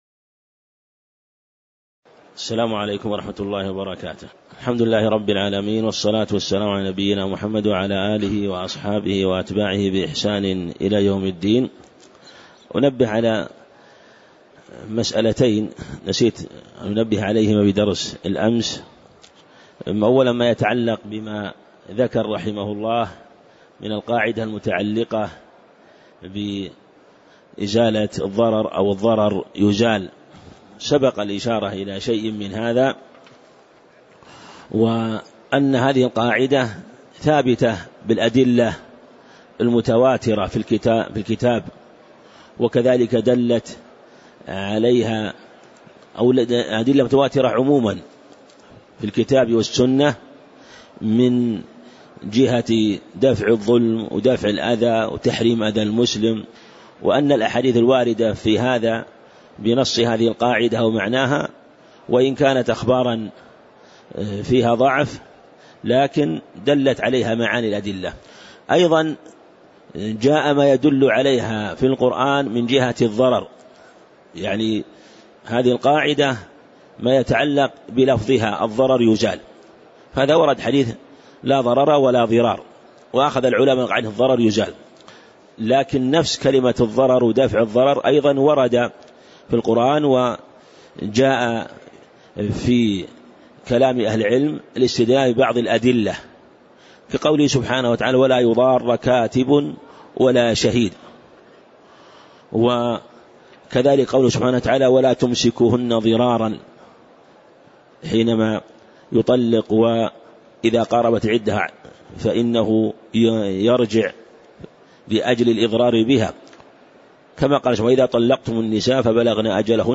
تاريخ النشر ٥ رجب ١٤٣٨ هـ المكان: المسجد النبوي الشيخ